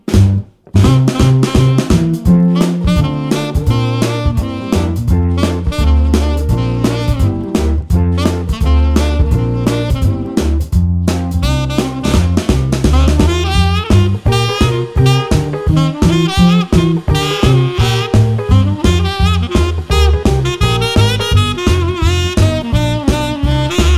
no Backing Vocals Rock 'n' Roll 2:24 Buy £1.50